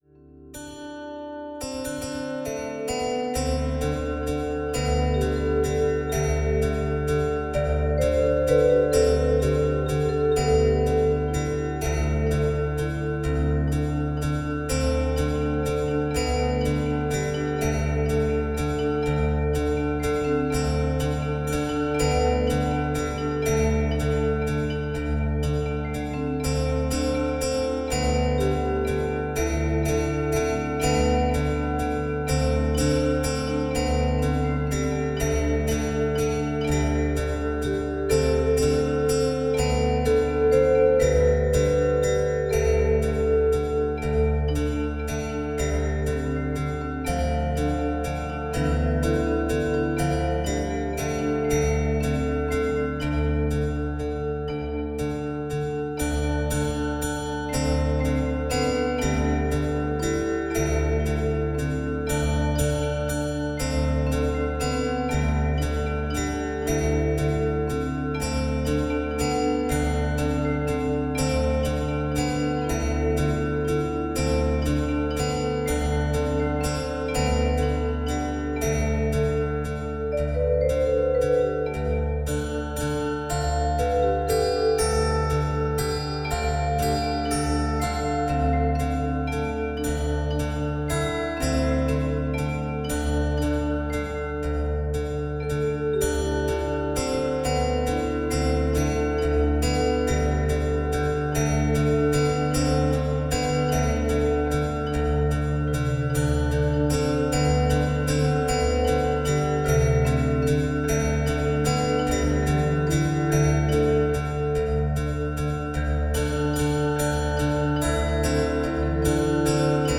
Tempo: 40/55 bpm / Datum: 22.11.2016